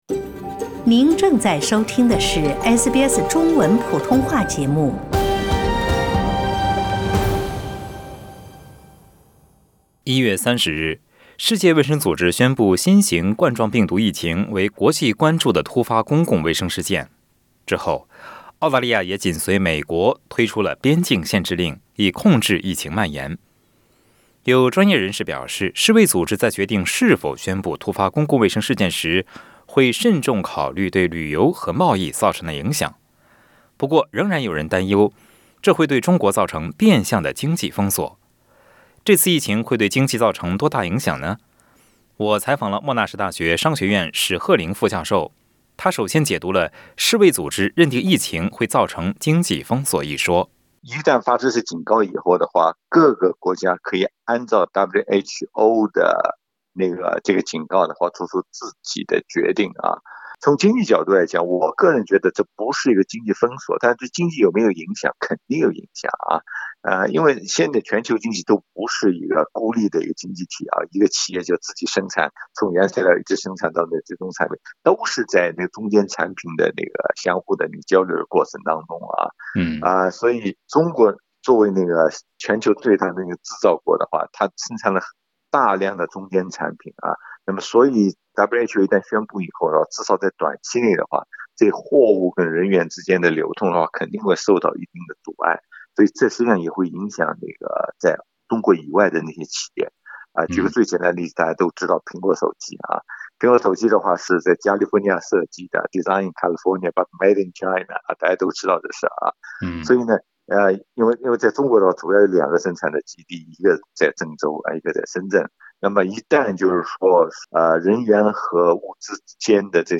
请您点击收听详细的采访内容。